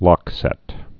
(lŏksĕt)